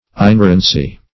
(Christianity) exemption from error ; - Example: "biblical inerrancy" The Collaborative International Dictionary of English v.0.48: Inerrancy \In*er"ran*cy\ ([i^]n*[e^]r"ran*s[y^]), n. Exemption from error.
inerrancy.mp3